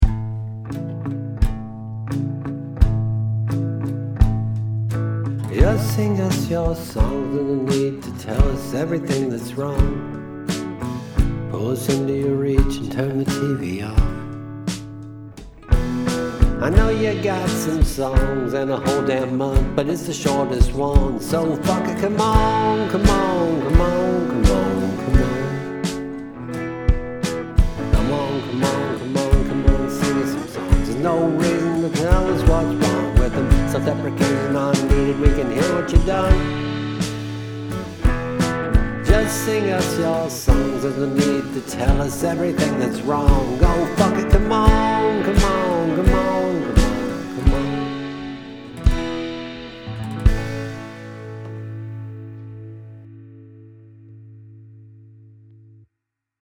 It's both inspirational and a lovely tune.